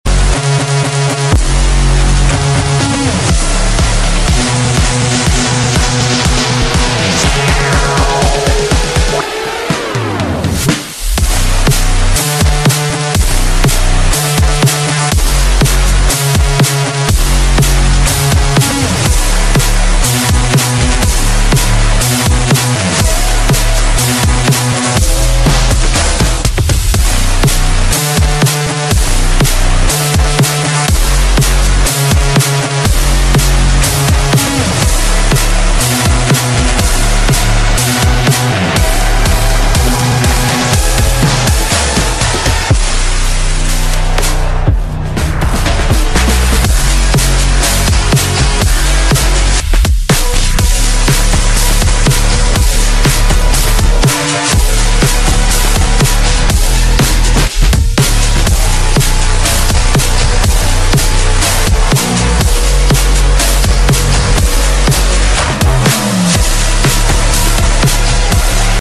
Daycore/Slowed